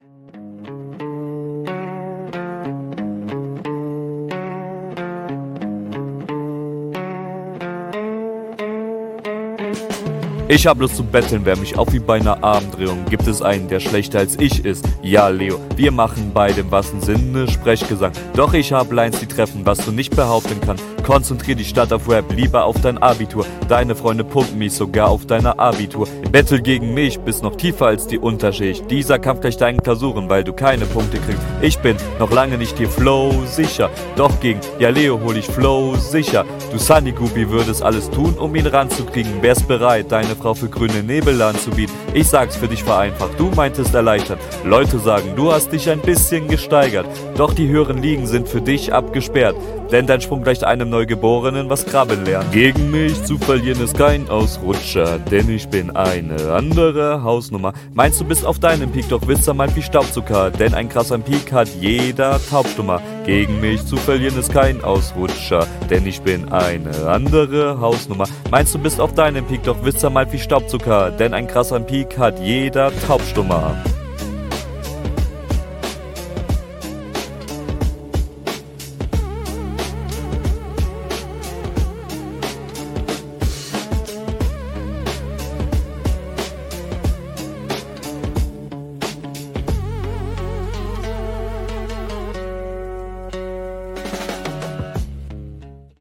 Cooler Beat.